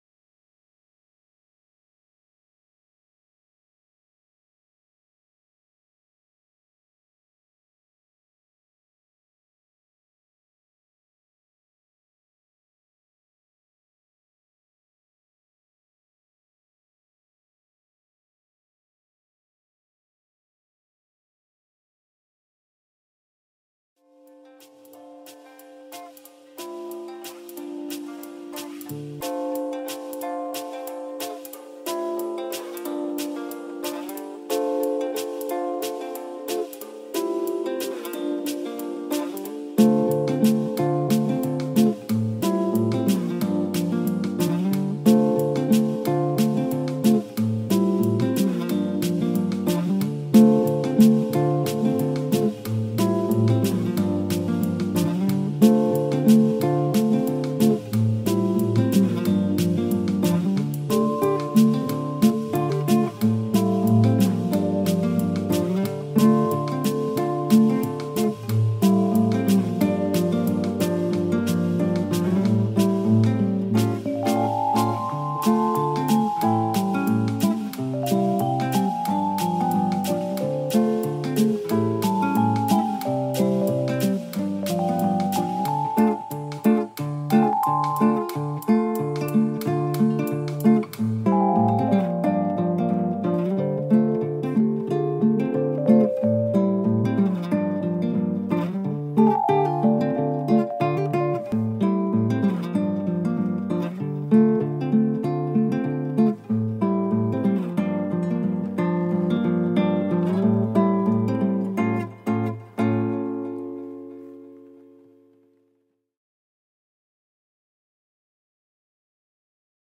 Live Q&A - It Just Gets Weirder
Welcome to the live Q&A, where it is never a dull moment!